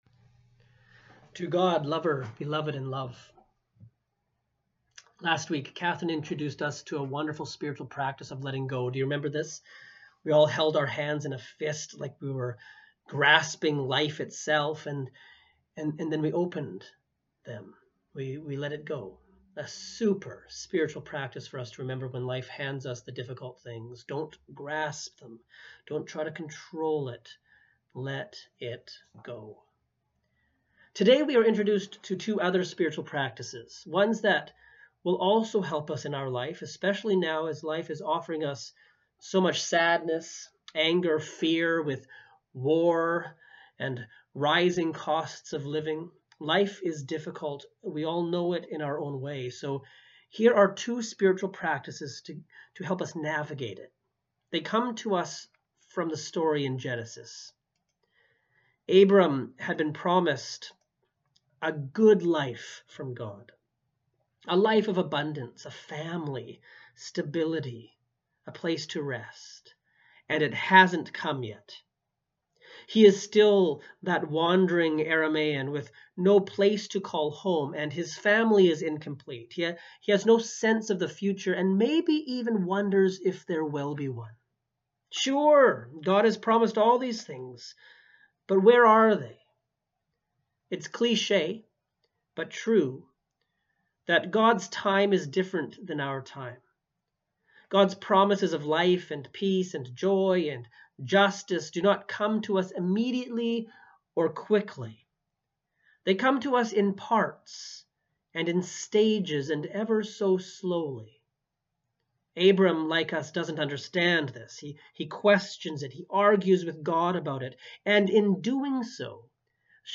Sermons | St. Dunstan's Anglican